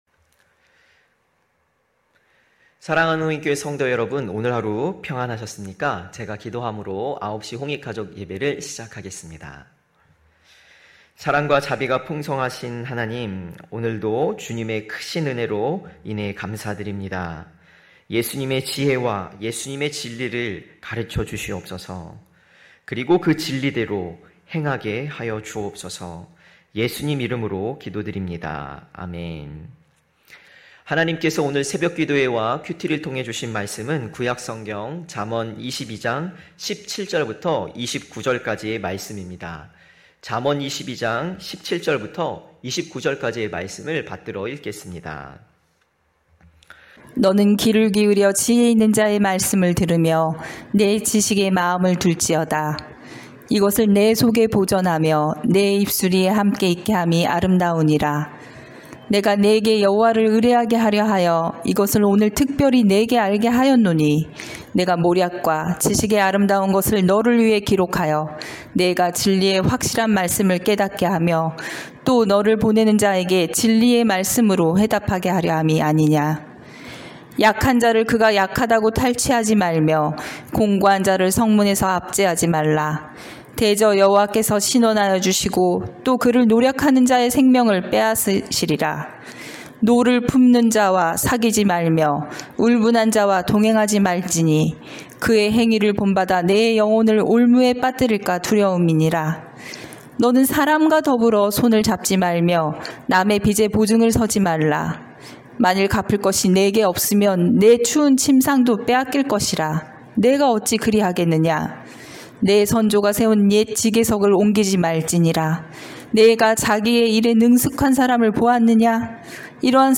9시홍익가족예배(6월12일).mp3